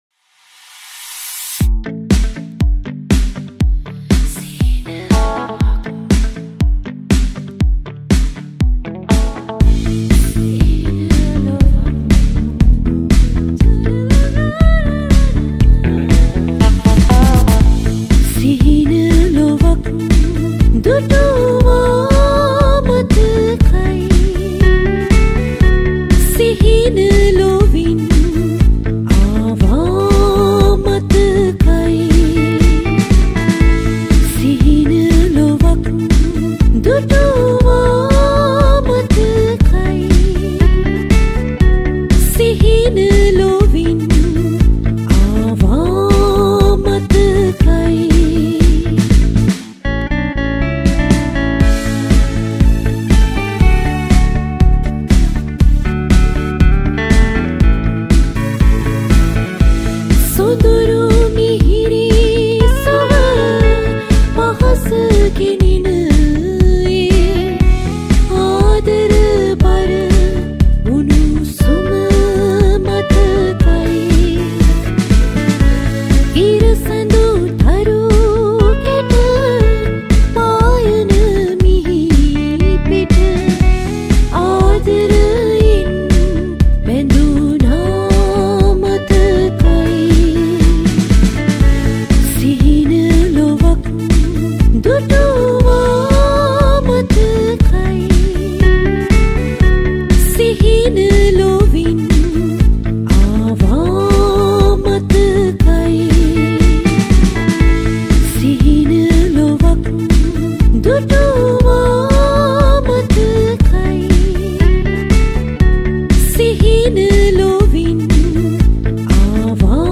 Original Vocals